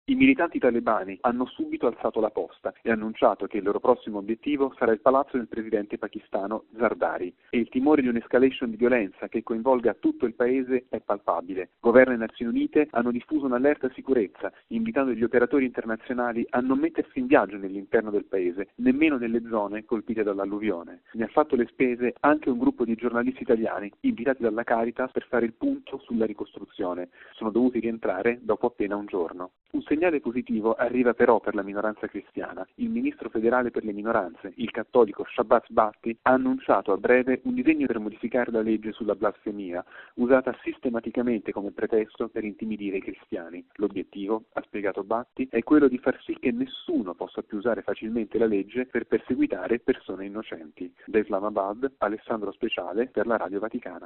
Il servizio dal Pakistan: RealAudio